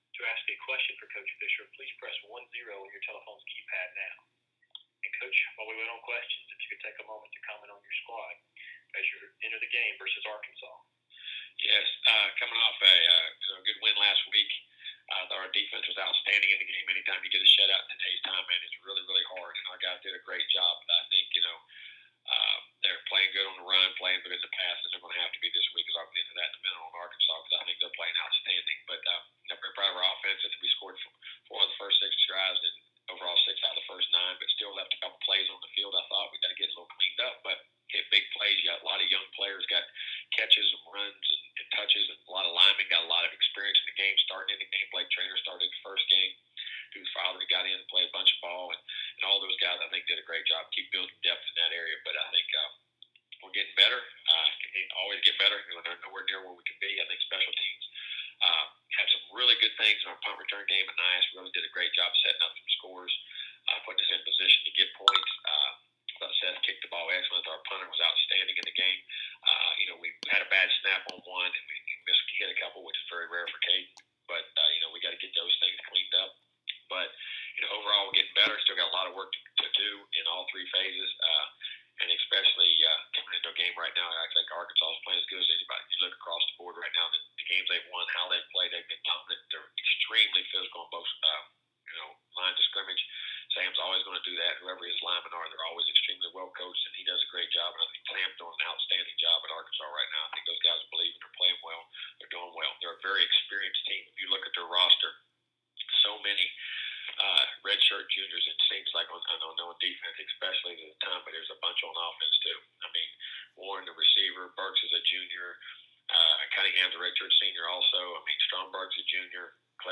Texas A&M coach Jimbo Fisher discussed his relationship with Arkansas' Sam Pittman, the Aggies' series against the Razorbacks and more on this week's SEC coaches teleconference.